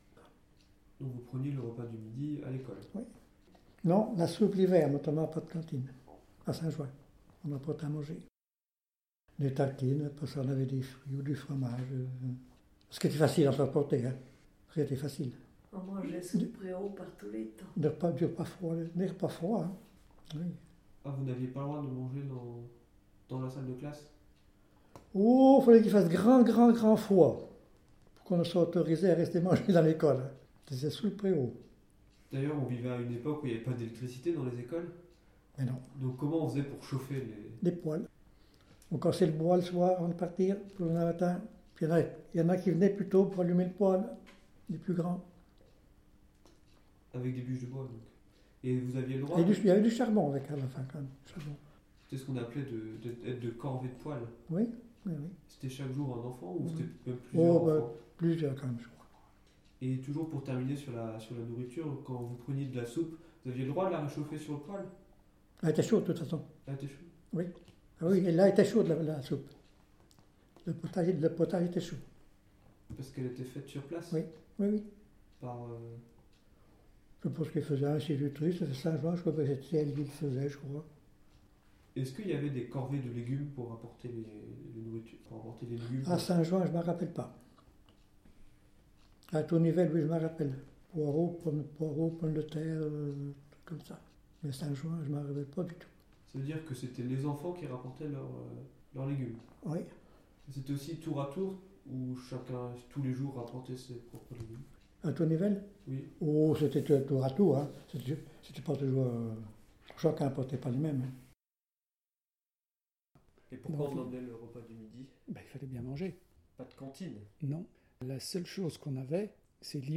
Collecte orale à la Tour Nivelle
Cette collecte, réalisée à partir d’un support sonore (dictaphone) et visuel (appareil photo) nous permet de constituer des collections, sous forme d’archives orales, supplémentaires pour le musée.